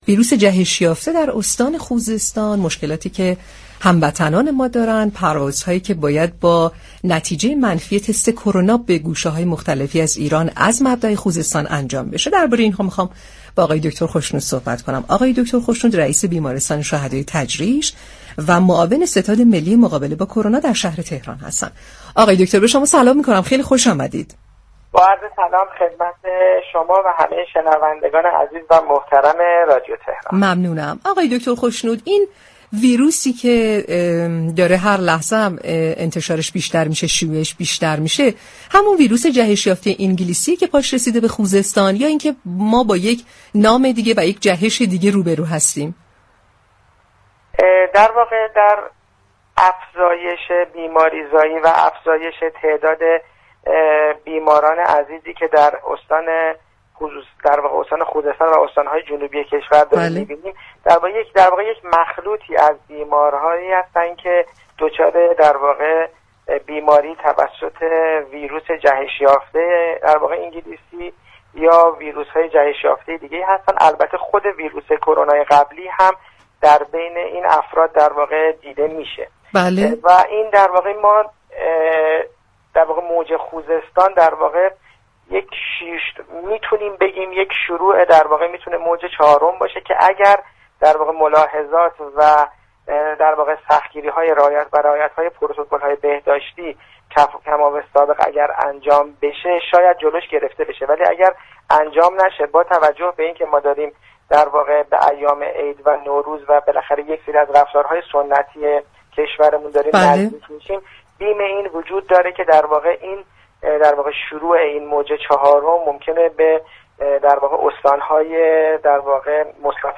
در گفتگو با برنامه تهران ما سلامت